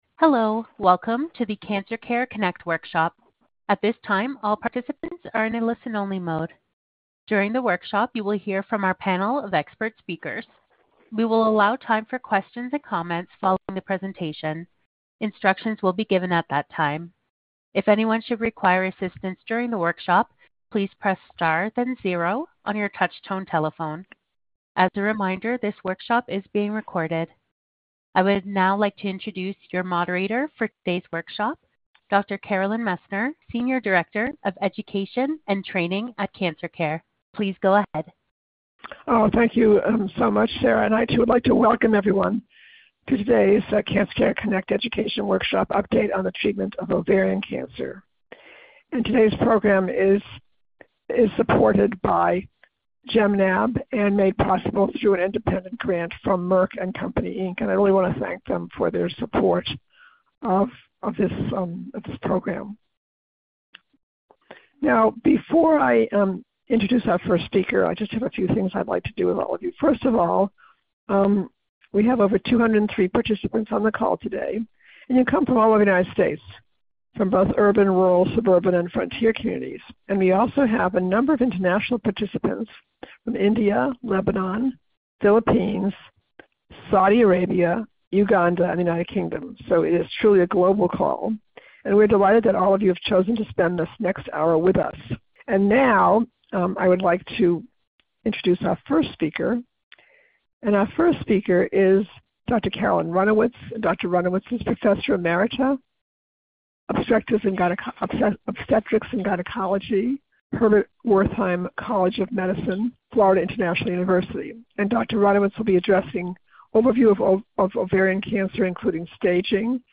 Questions for Our Panel of Experts
This workshop was originally recorded on February 25, 2025.